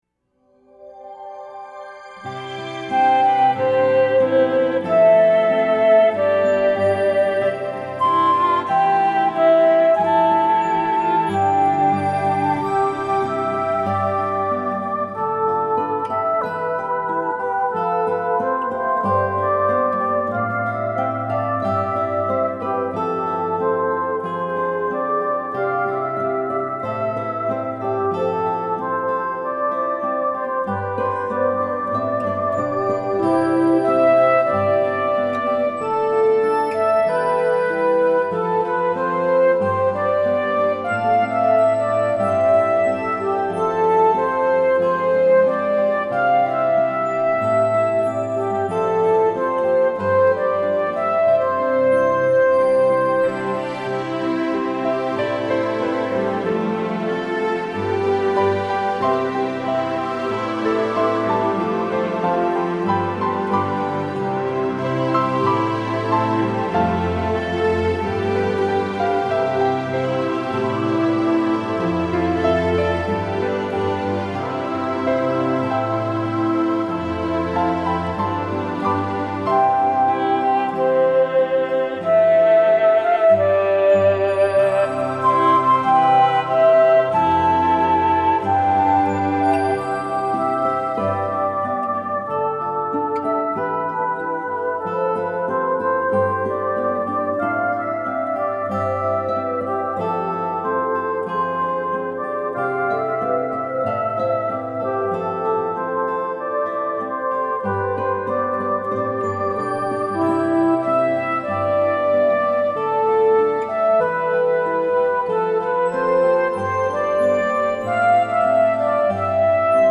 ANOTHER YOU #3 INSTRUMENTAL